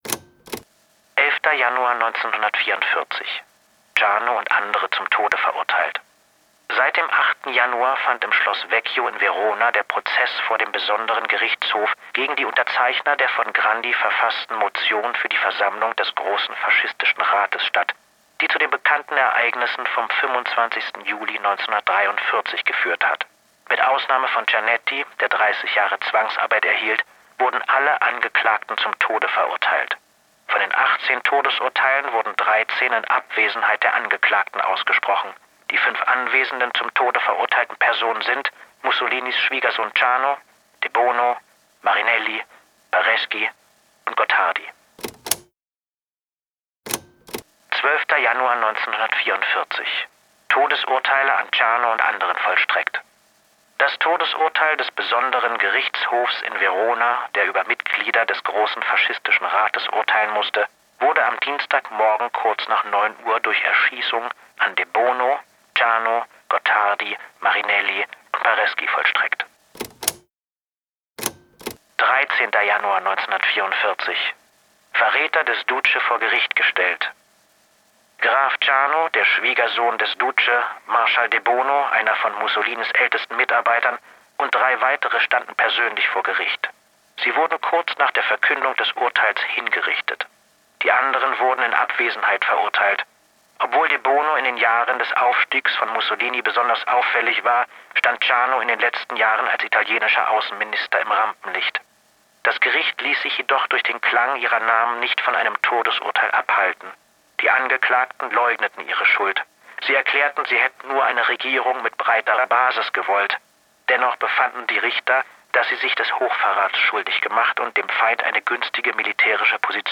performed by Fabian Busch